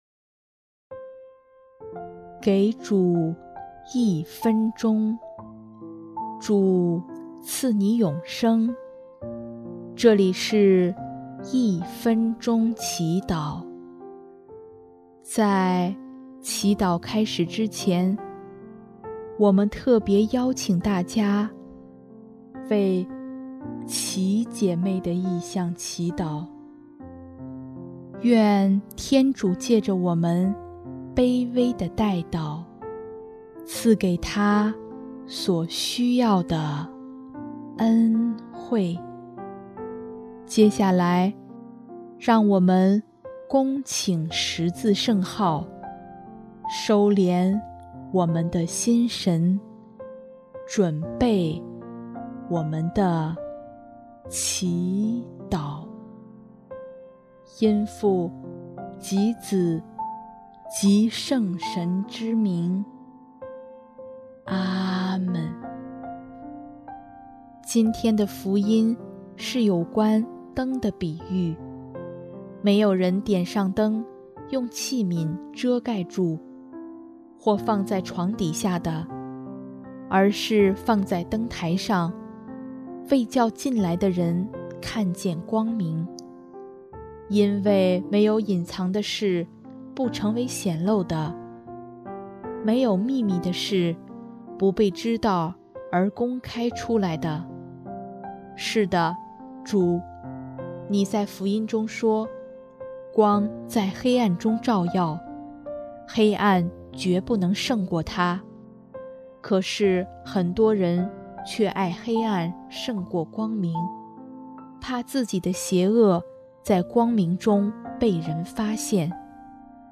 音乐：第一届华语圣歌大赛参赛歌曲《生命的亮光》